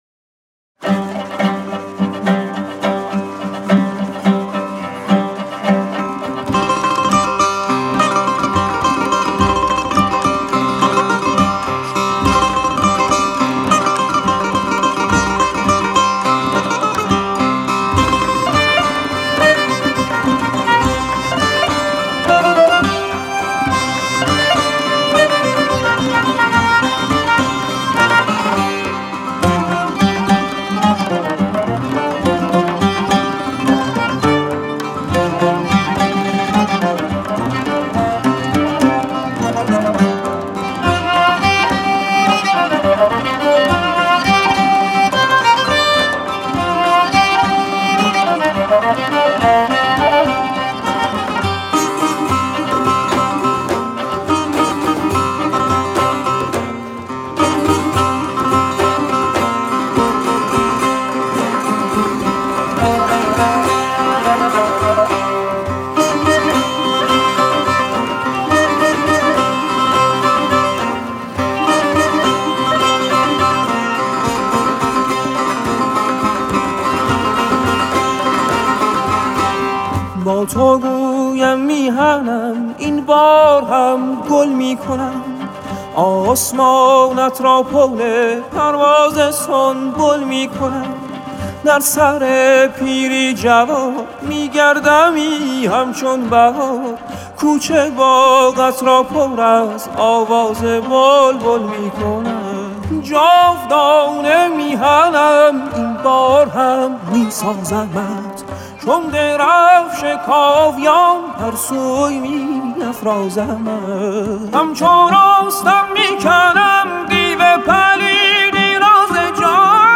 این قطعه در ریتم پنج ضربی
عود
کمانچه
در فضای دستگاه شور و  آواز دشتی